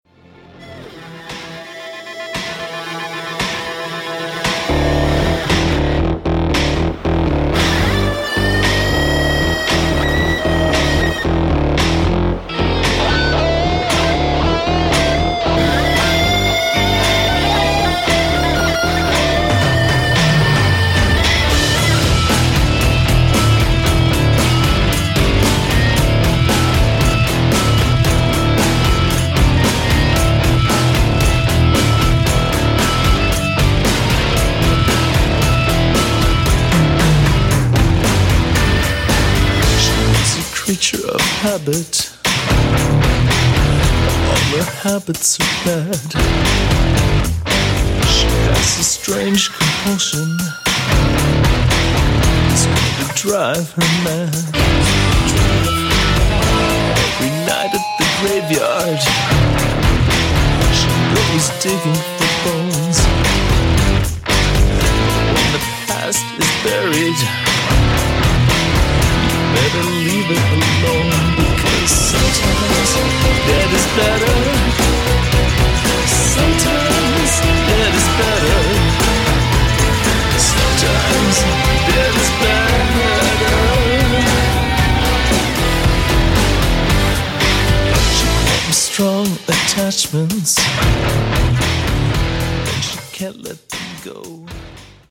classic goth